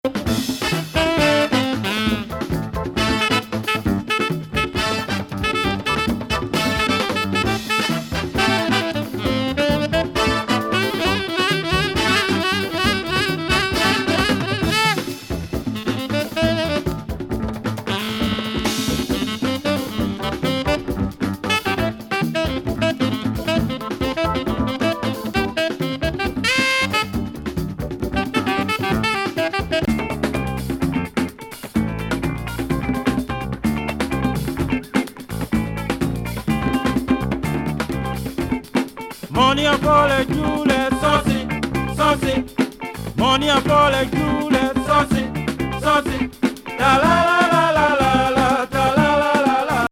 続いてガーナのパーカッション奏者のアフロ・パーカッション全開作！
ホーンも絡む疾走グルーブ